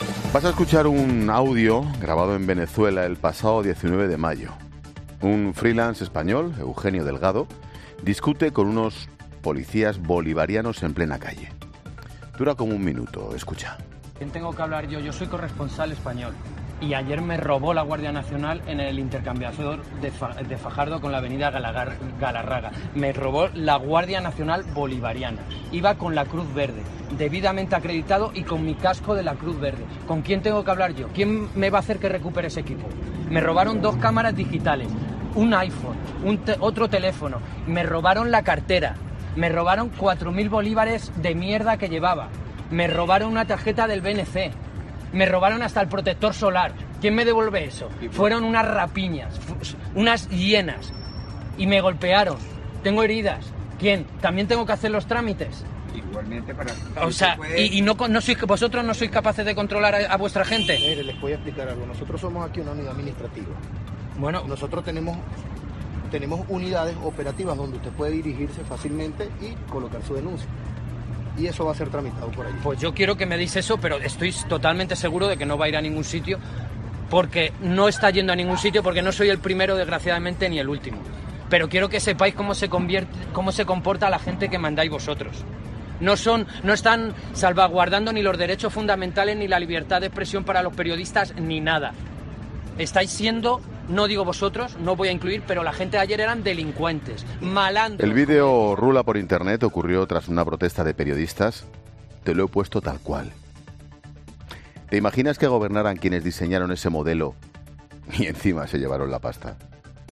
Monólogo de Ángel Expósito a las 17h. con el testimonio de un periodista denunciando el robo y amenazas que ha sufrido por parte de la Guardia Nacional Bolivariana de Venezuela.